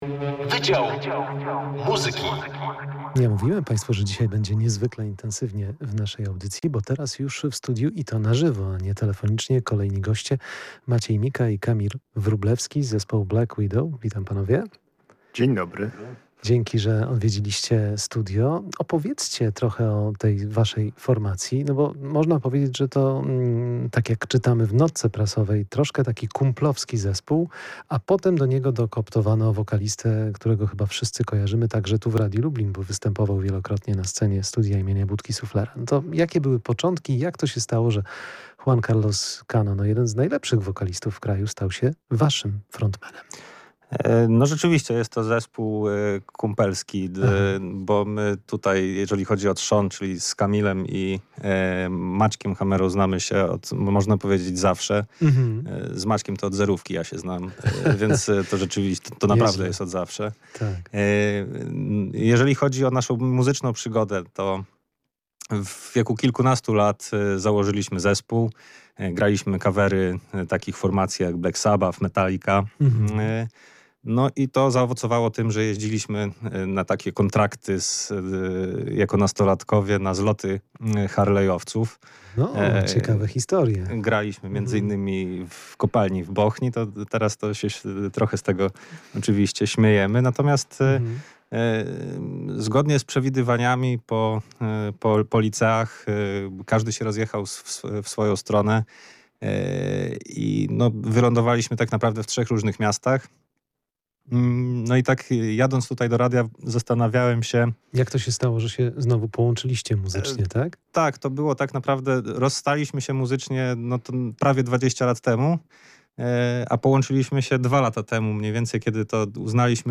Muzyka pozbawiona kompromisów, twarda ale pełna emocji.